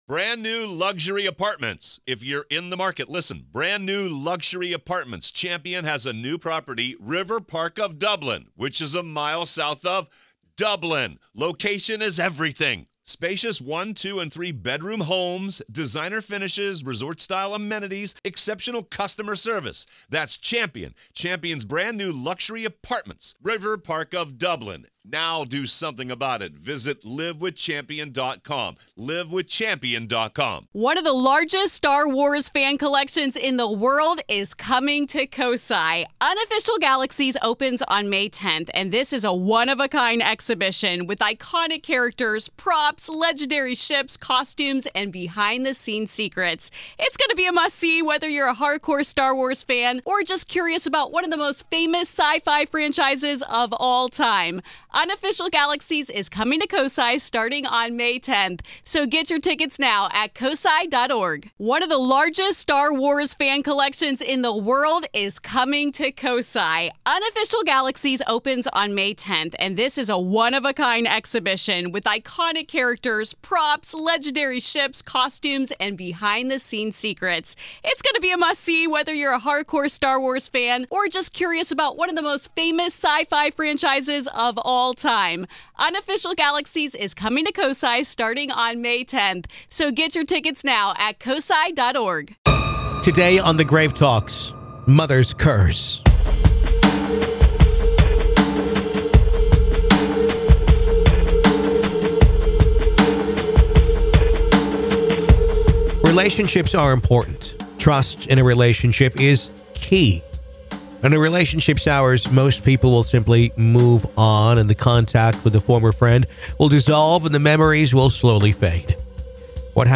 What Caused The Curse On A Mother? PART 2 - AVAILABLE TO GRAVE KEEPERS ONLY - LISTEN HERE In part two of our interview, available only to Grave Keepers, we discuss: